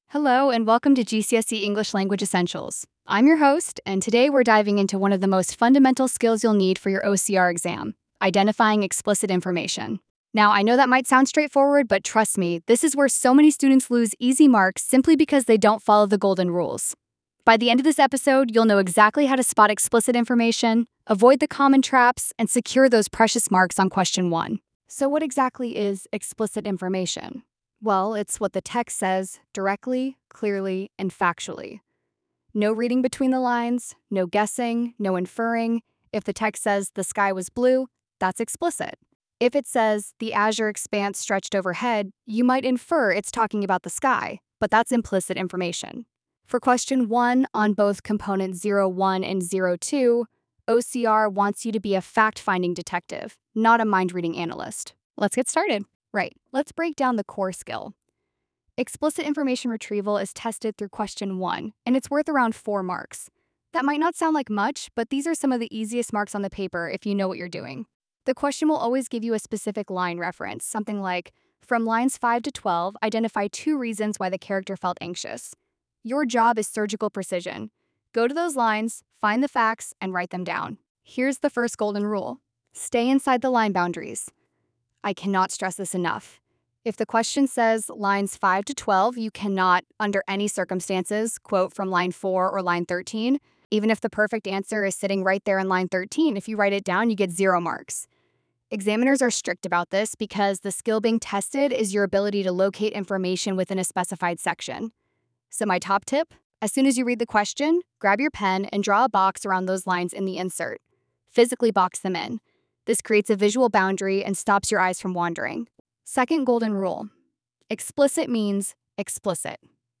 Listen to our expert tutor explain how to master AO1.